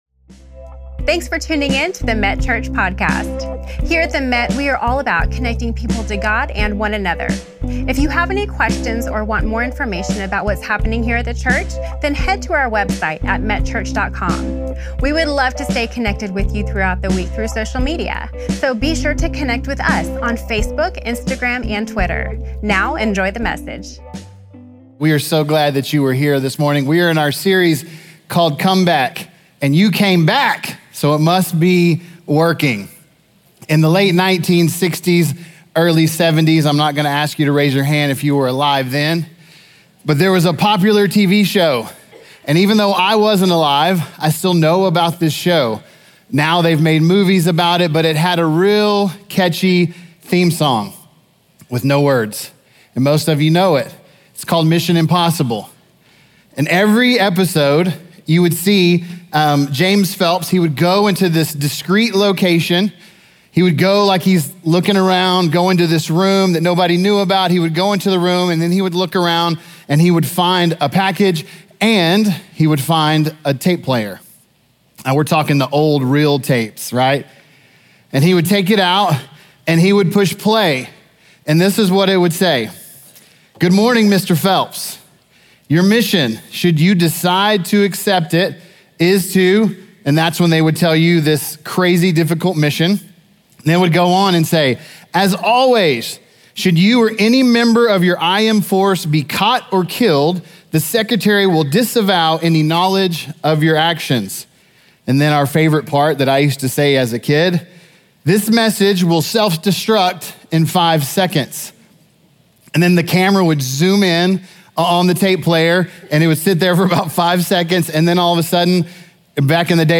Executive Outreach Pastor